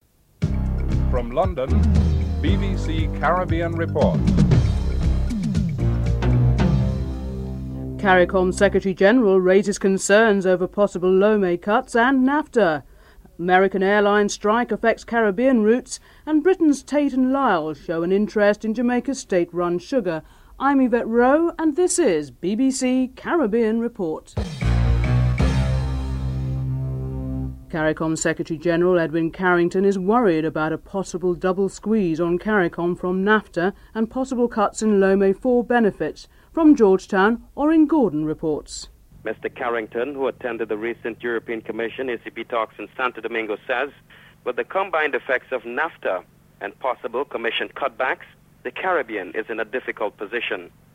Headlines